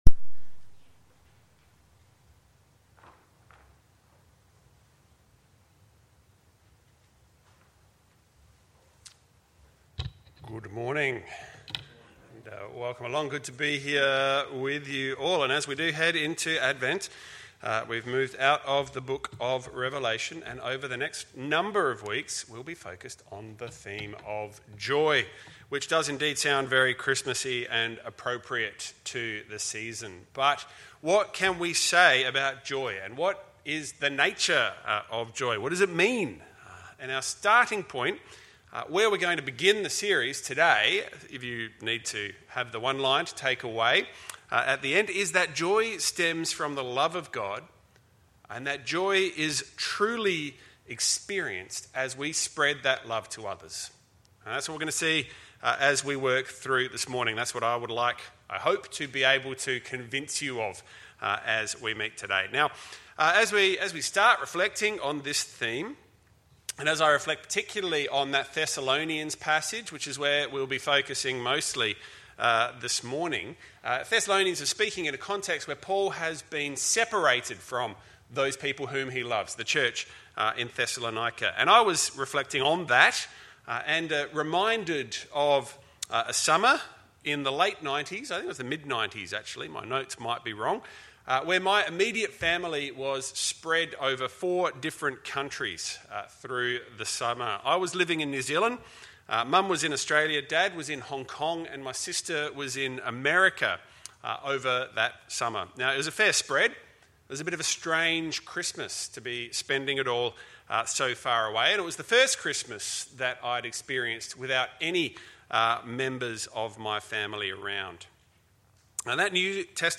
Sermons by St Matthew's Anglican Church